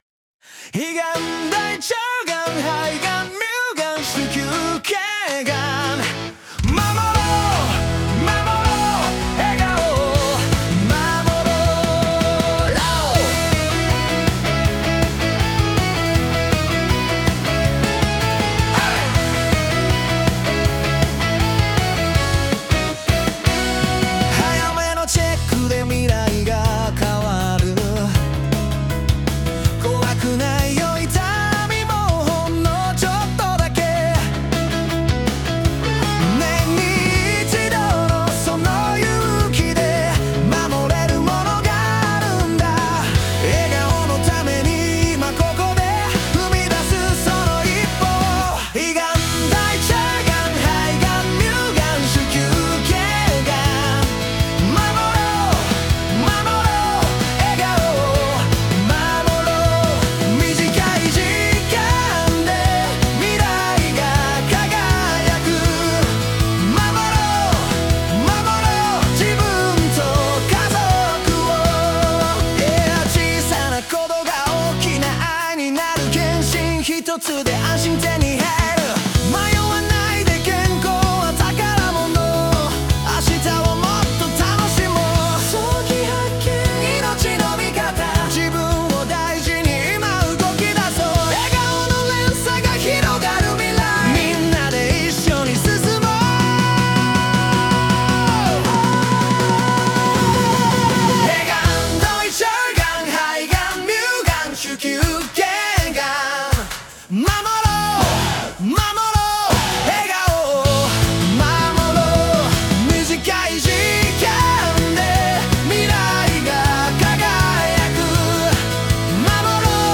適切ながん健診を推奨する歌をSUNOで作りました。